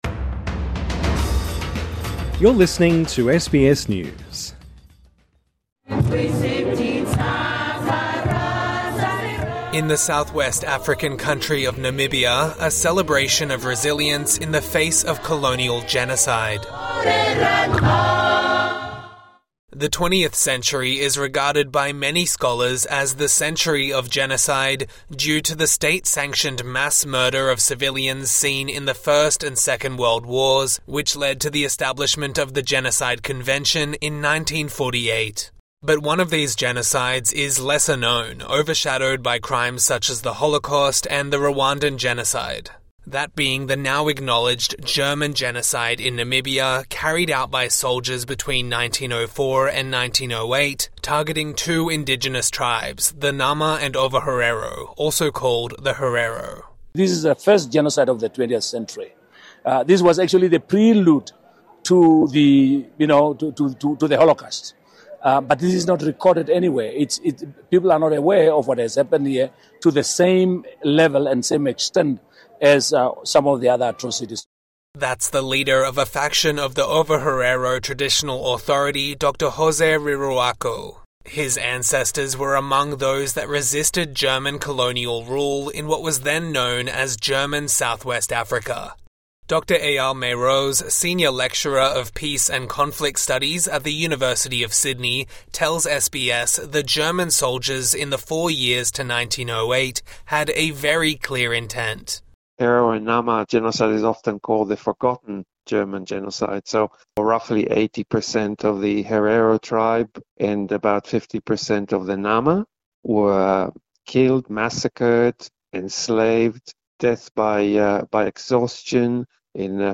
TRANSCRIPT (Sounds of people singing) In the southwest African country of Namibia, a celebration of resilience in the face of colonial genocide.